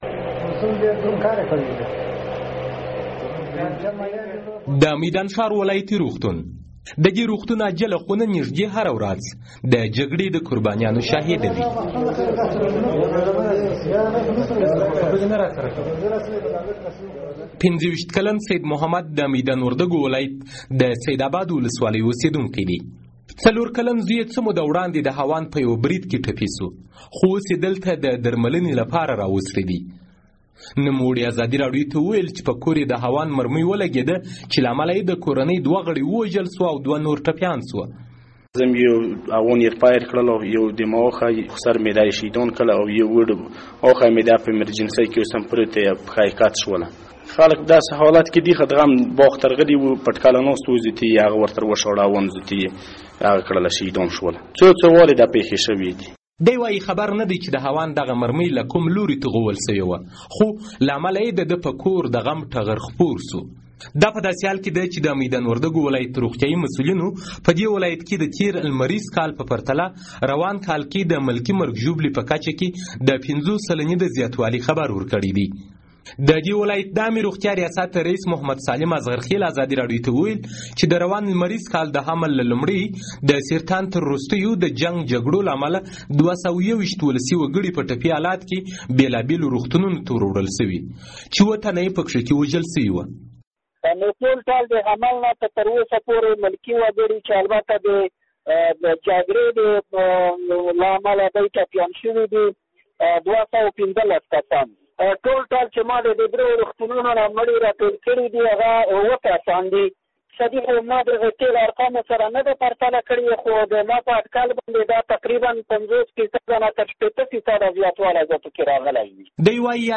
د میدان وردګو راپور